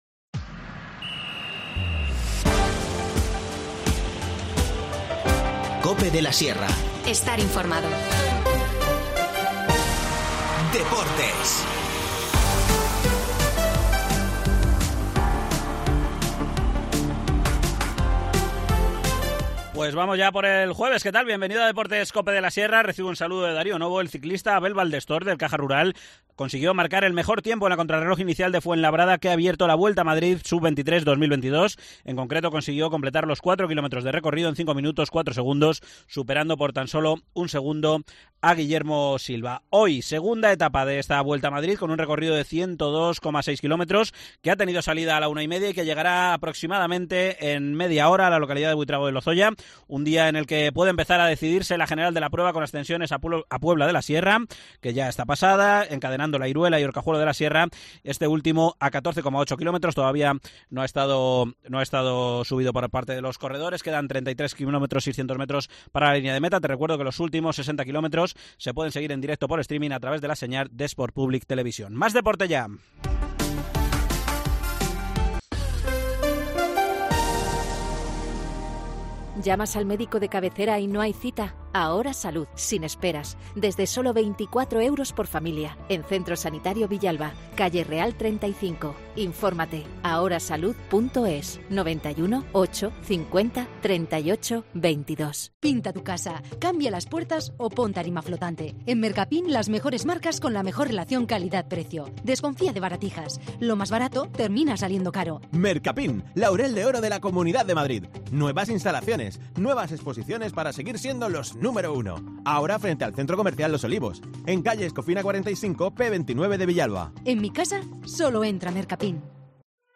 Este fin de semana, II Memorial Darío Novo de Voley 4x4 mixto y XII Marcha Benéfica Abantos Trekking. Nos lo cuenta el concejal de Deportes, Miguel Ángel Montes.